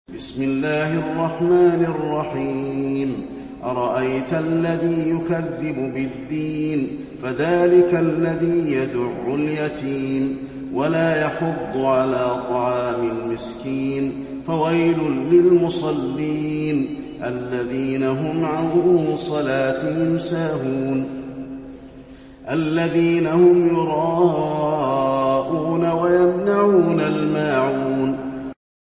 المكان: المسجد النبوي الماعون